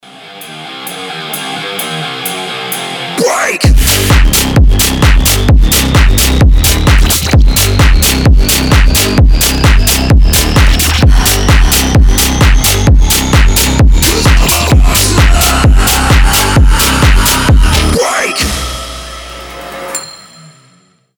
Метал + хаус